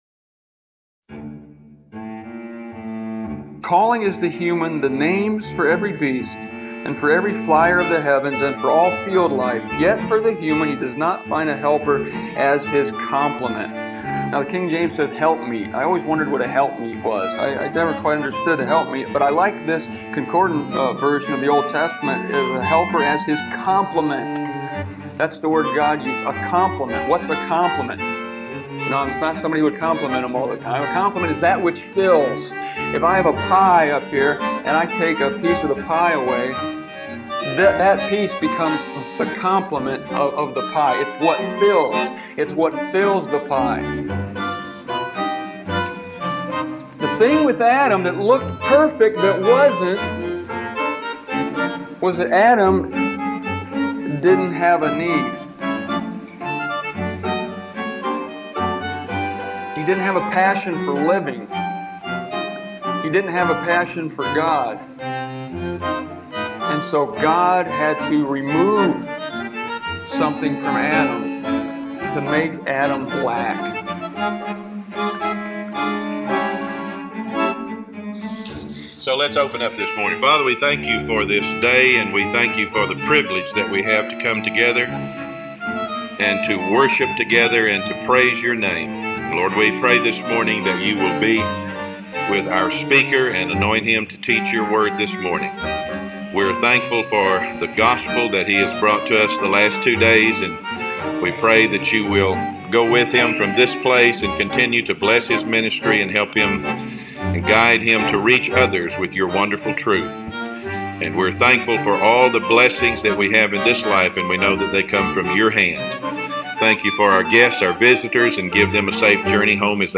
I recorded this message twenty-one years ago in Irving, Texas.